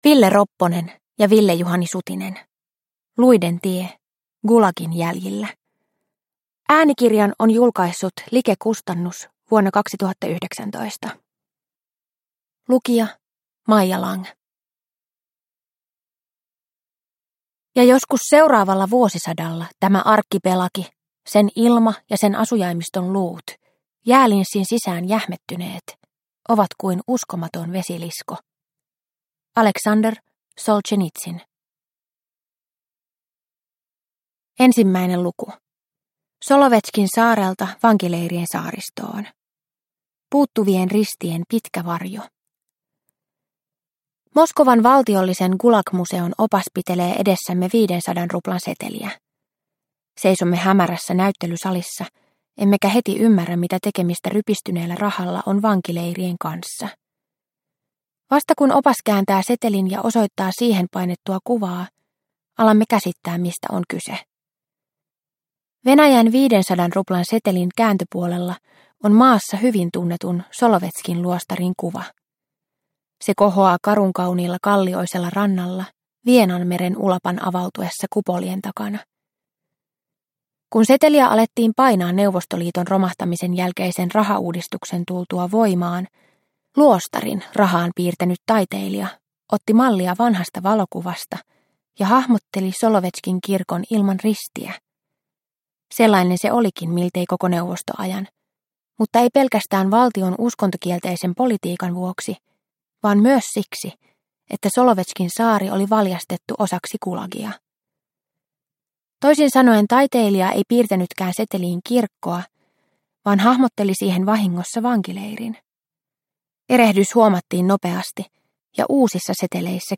Luiden tie – Ljudbok – Laddas ner